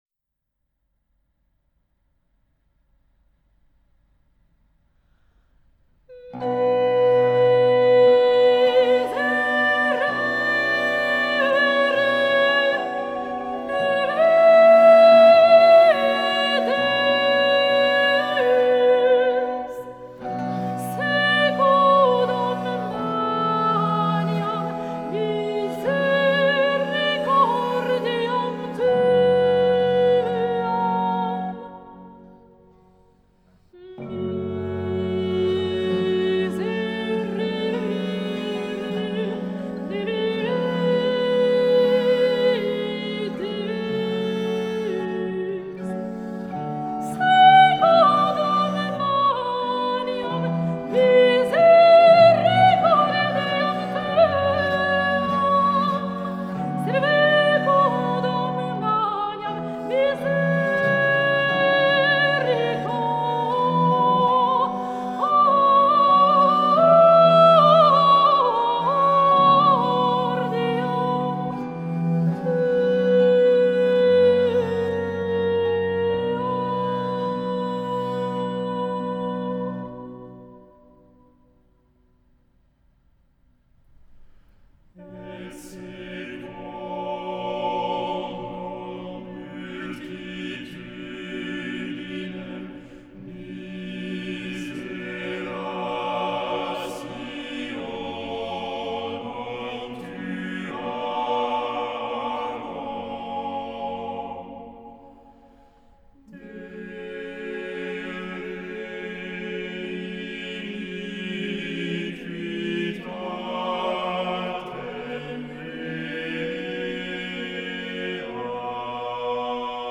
01-miserere-c3a0-voix-seule.mp3